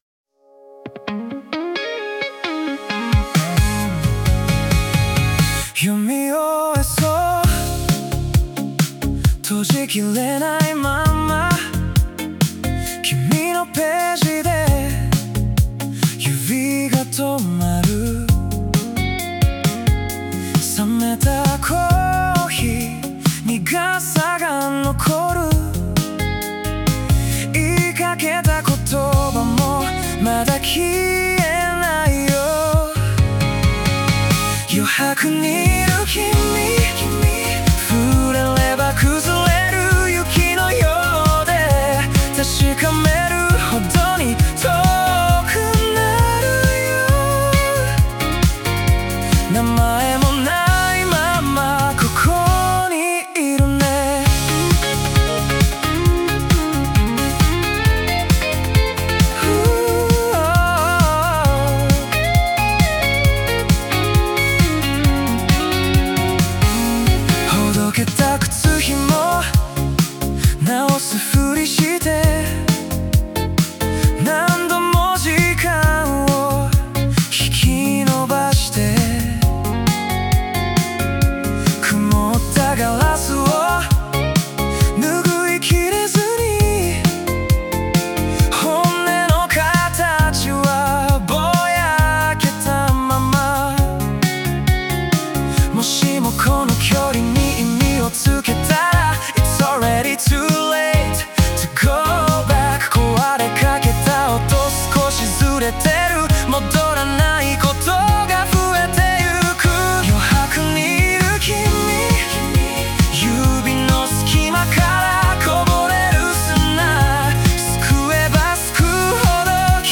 イメージ：ポップス,男性ボーカル,切ない,コンテンポラリーポップ